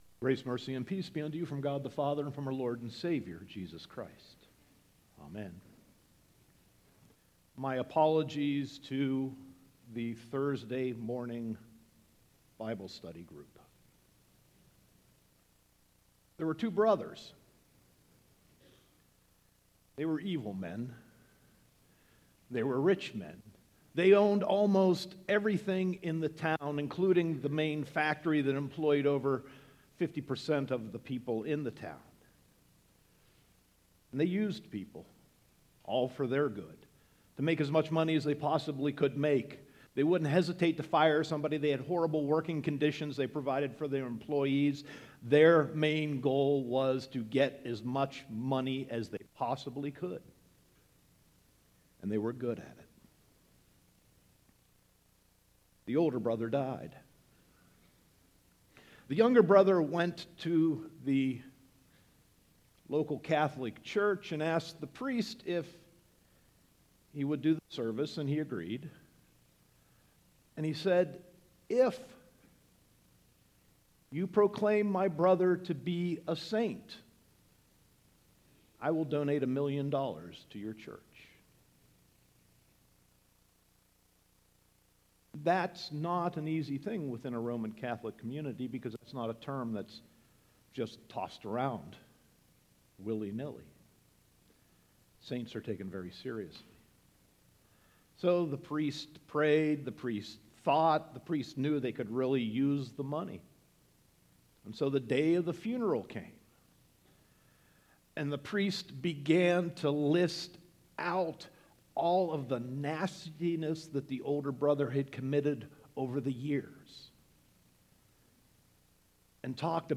Sermon 11.5.2017